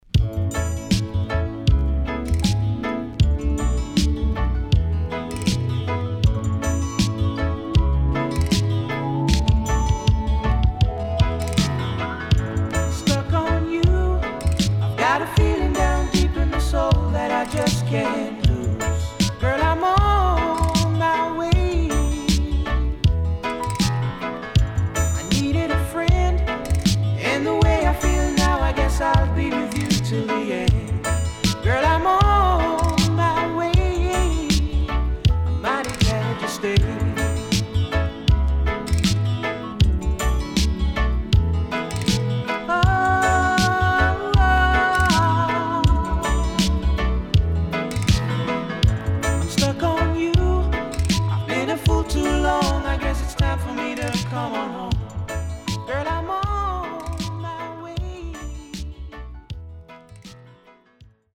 【12inch】
SIDE A:少しチリノイズ入りますが良好です。